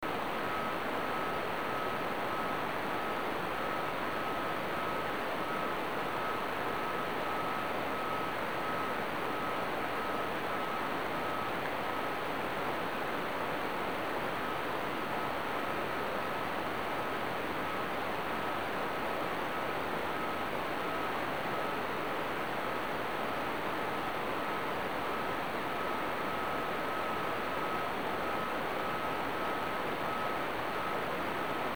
г). MSF (Англия) - частота 60 кГц.
На пределе слышмости можно послушать сигнал, скачав
60KHz_MSF.mp3